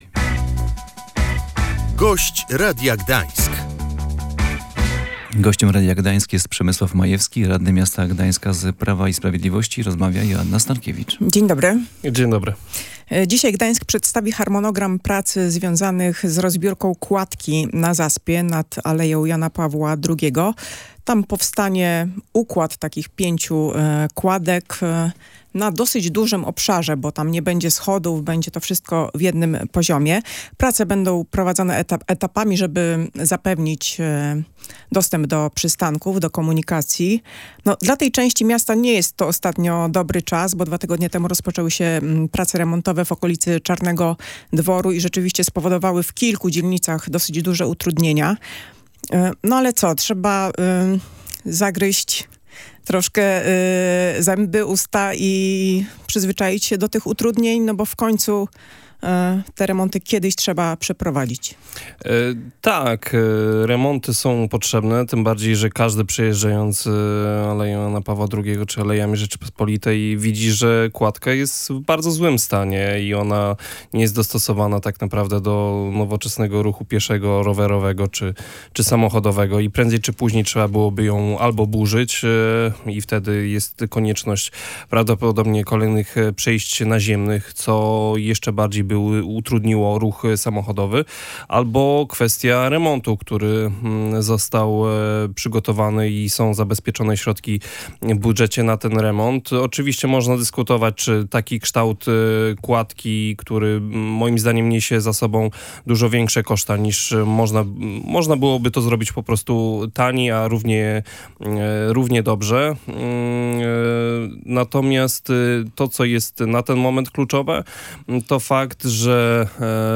Kierowcy nie po to płacą w Gdańsku podatki, by na nich testowano ruch. Władze miasta dysponują różnego rodzaju analizami i narzędziami, by odpowiednio zaplanować komunikację – wskazał na antenie Radia Gdańsk gdański radny Prawa i Sprawiedliwości Przemysław Majewski.